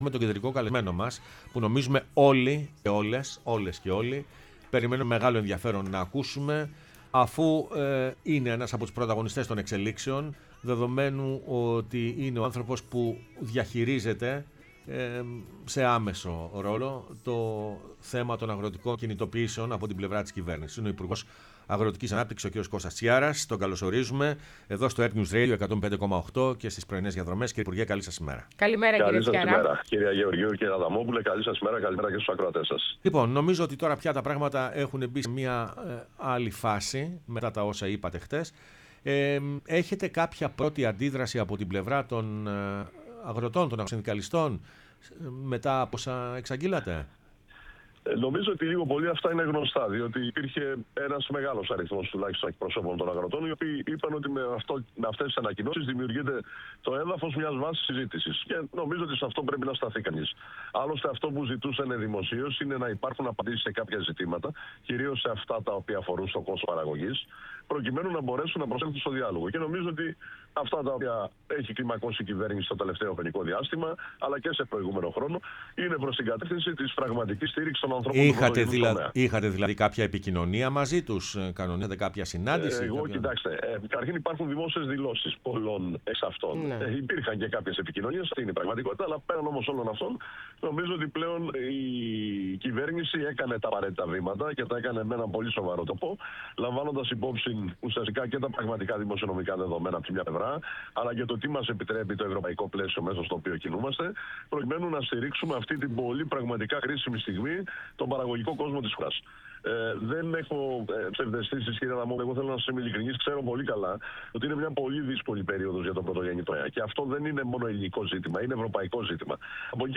Κώστας Τσιάρας, Υπουργός Αγροτικής Ανάπτυξης, μίλησε στην εκπομπή “Πρωινές Διαδρομές”